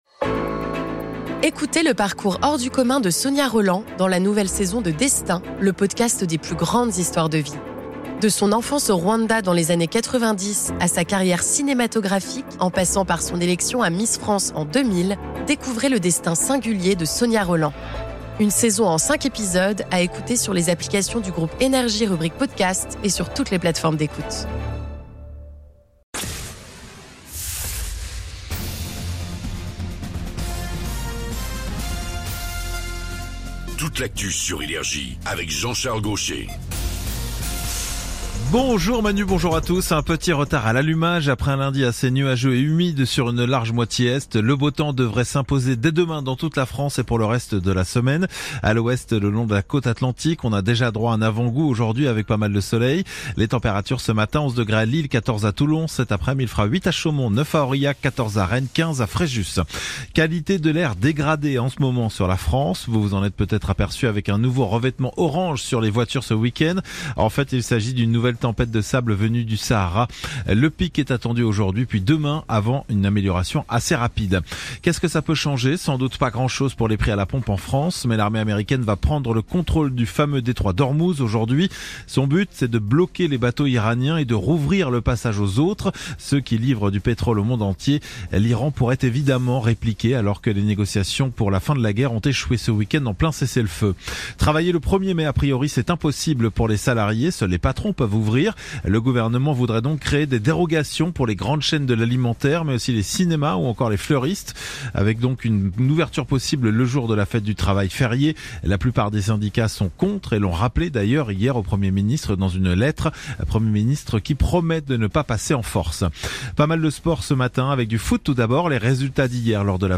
Genres: News